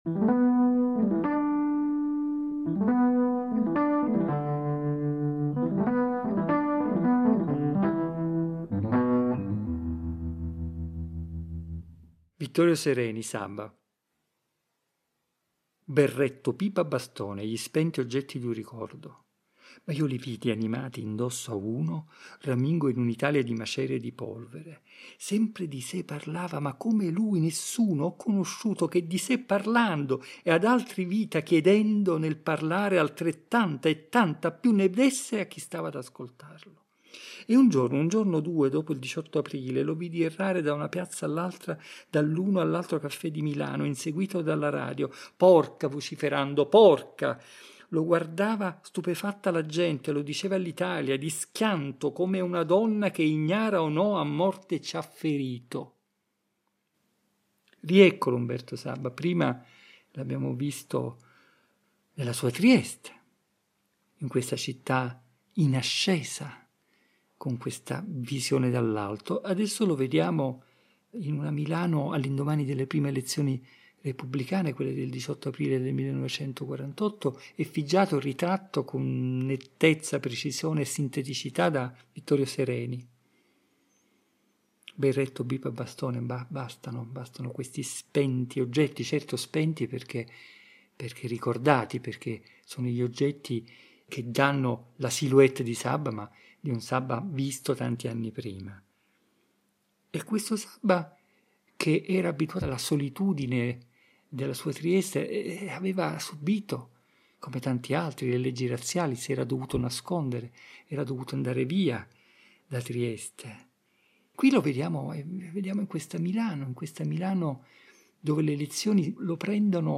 Versi scelti e raccontati
Ed è a partire da questo simbolo "delle equazioni casalinghe" che hanno caratterizzato il tempo del lockdown (ogni registrazione è stata pensata e realizzata fra le mura domestiche) che egli ci guida nella rigogliosa selva della parola poetica per "dare aria ai pensieri".